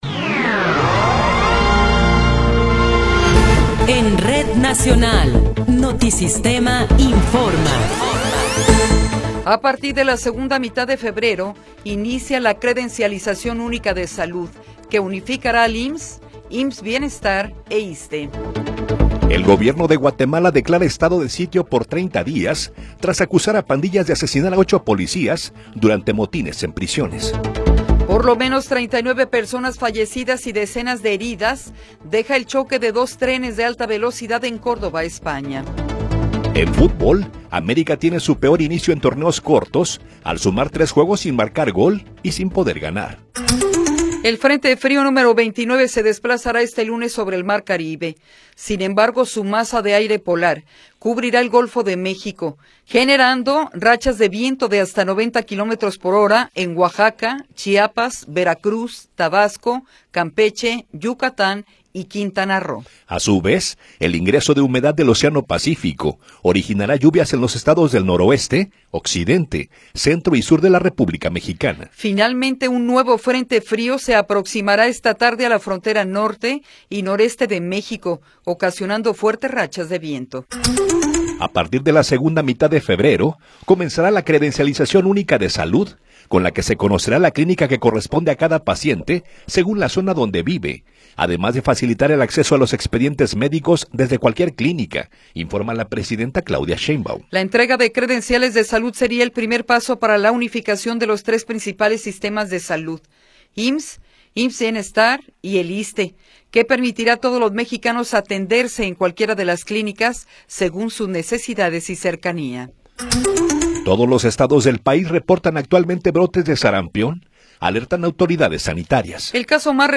Noticiero 8 hrs. – 19 de Enero de 2026
Resumen informativo Notisistema, la mejor y más completa información cada hora en la hora.